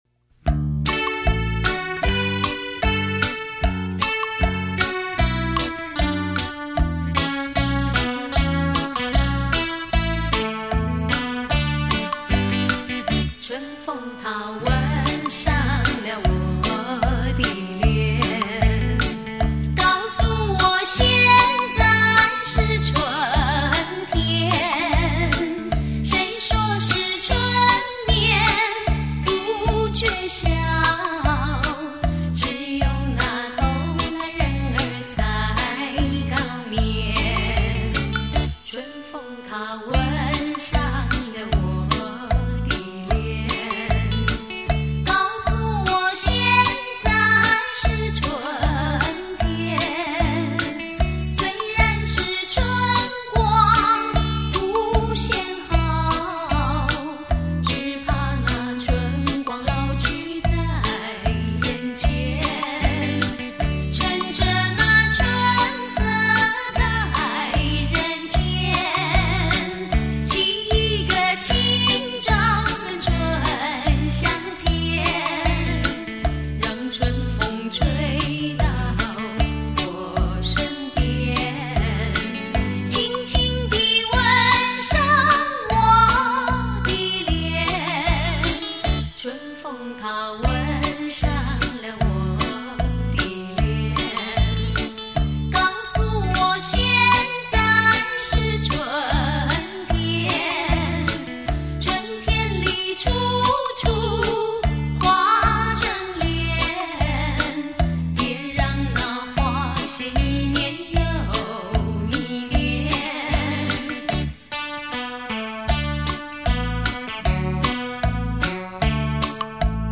收录美好的回忆谱出盈盈的浪漫情怀
动听的歌声， 如梦般的永恒的旋律